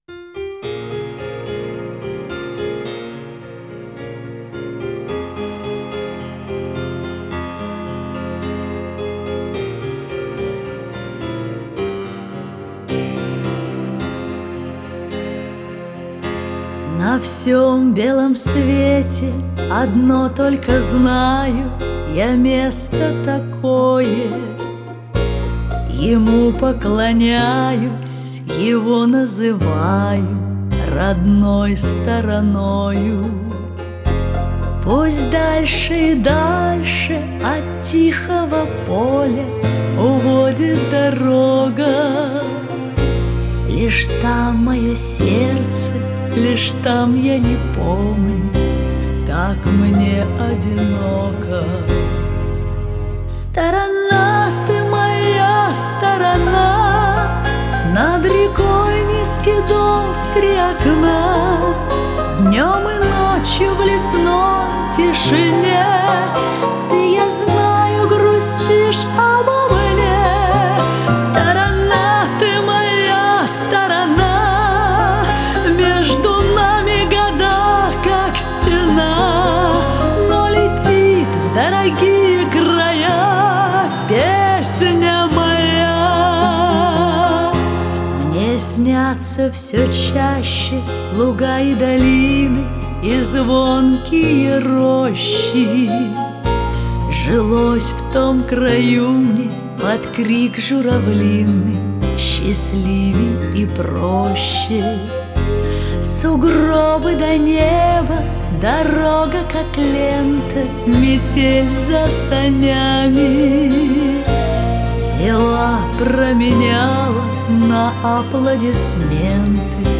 российская поп-певица